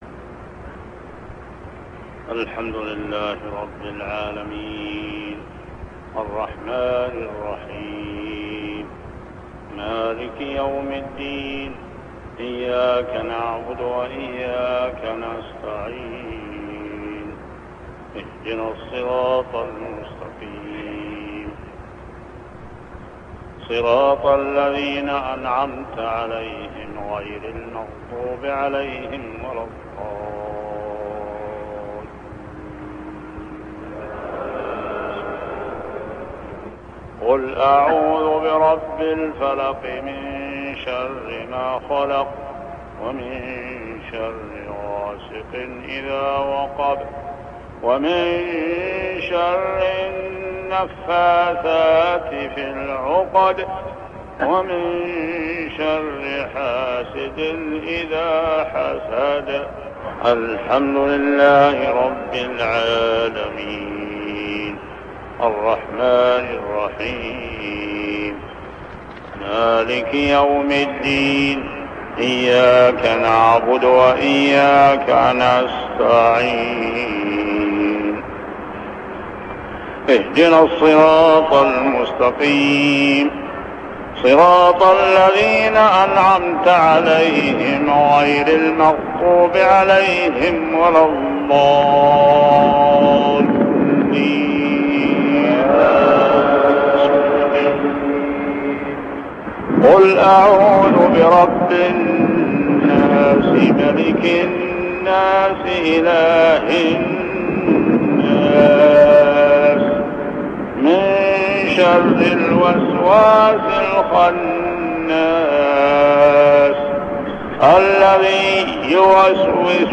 صلاة المغرب عام 1399هـ سورتي الفلق و الناس كاملة | maghrib prayer Surah Al-Falaq and An-Nas > 1399 🕋 > الفروض - تلاوات الحرمين